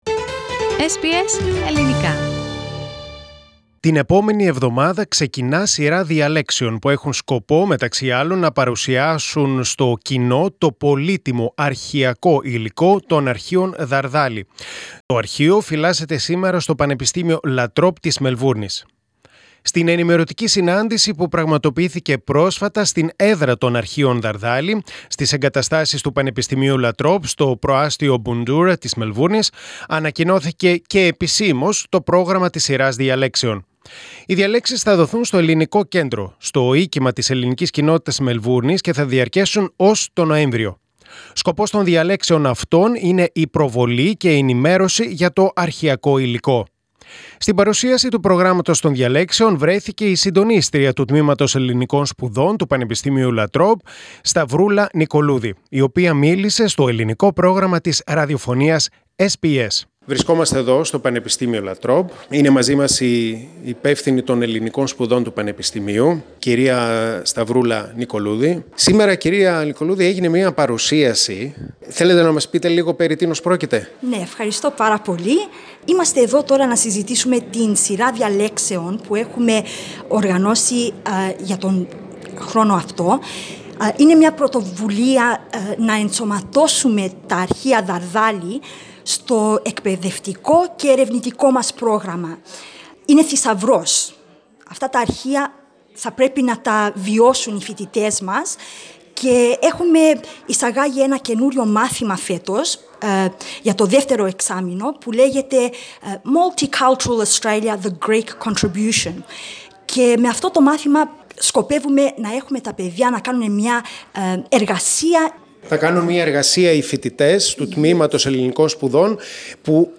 μίλησε στο Ελληνικό Πρόγραμμα της Ραδιοφωνίας SBS.